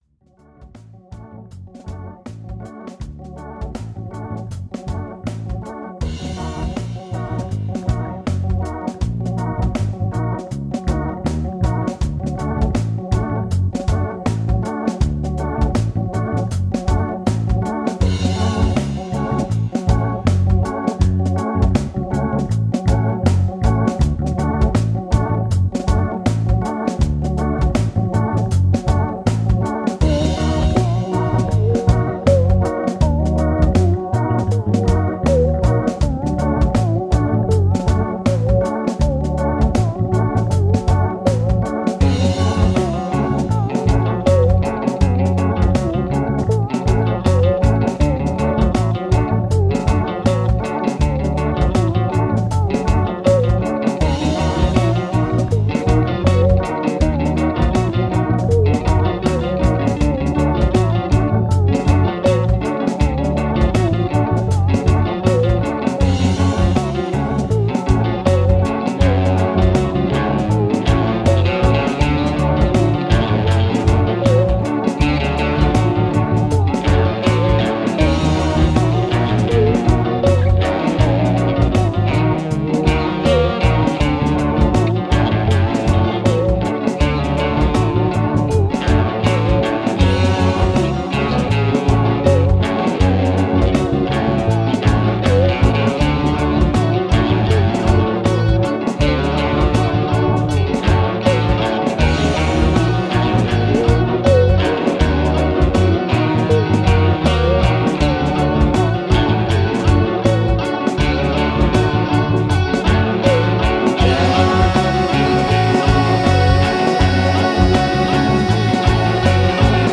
It’s just a short instrumental.